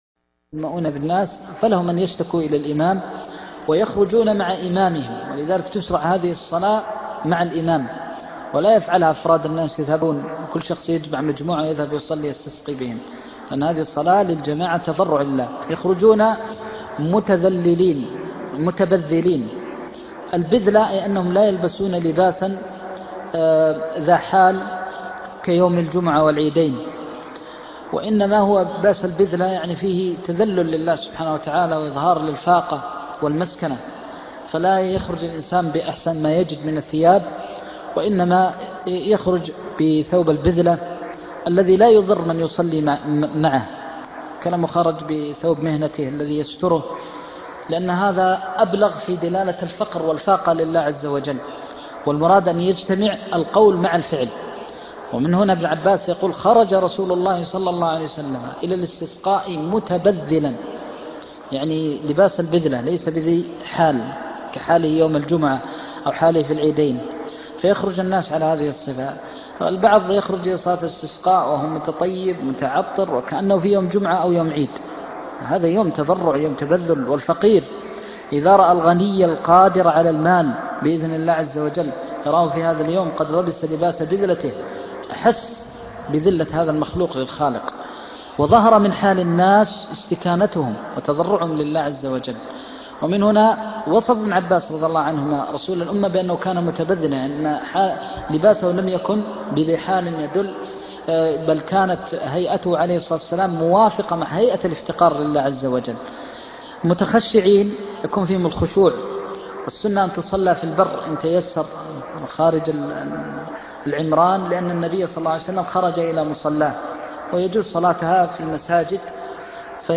درس عمدة الفقه (13) - تابع كتاب الصلاة - الشيخ محمد بن محمد المختار الشنقيطي